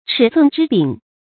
尺寸之柄 chǐ cùn zhī bǐng
尺寸之柄发音